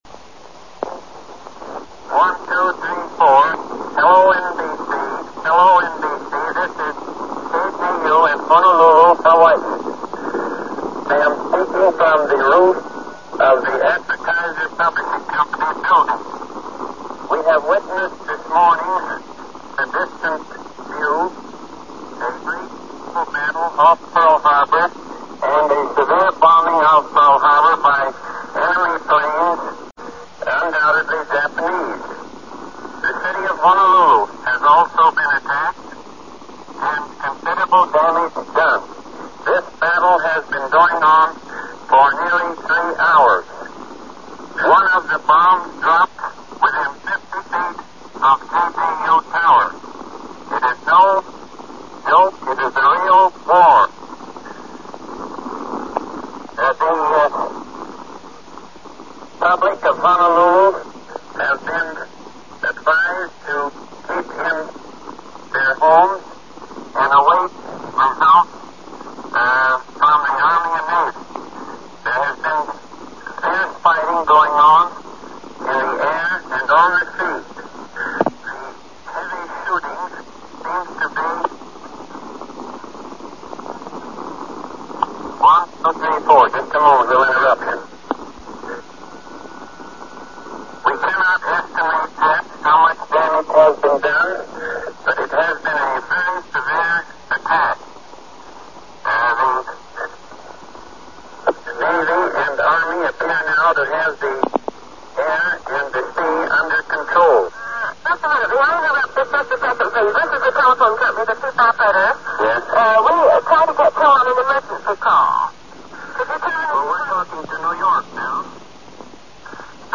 Attack on Pearl Harbor live coverage from KGU Radio, Honolulu, Hawaii, December 7, 1941